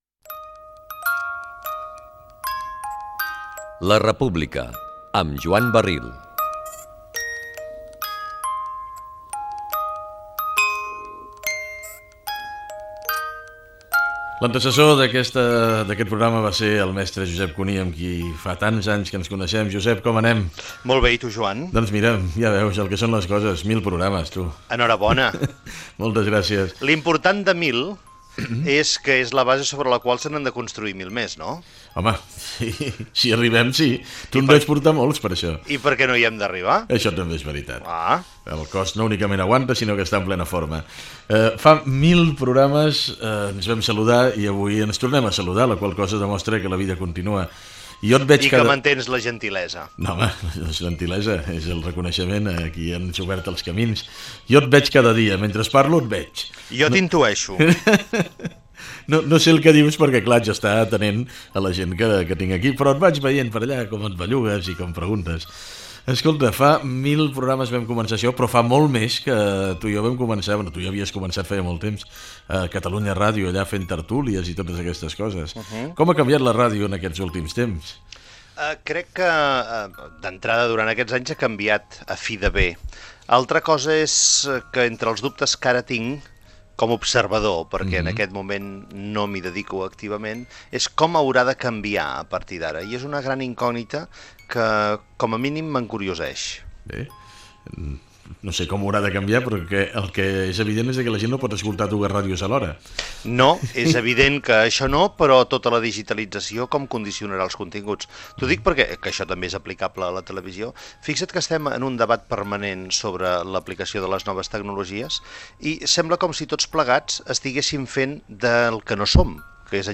Info-entreteniment
FM